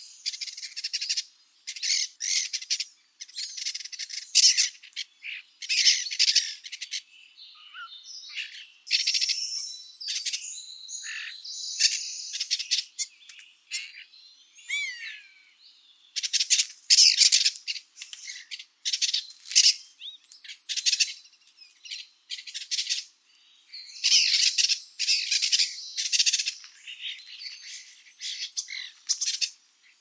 etourneau-sansonnet.mp3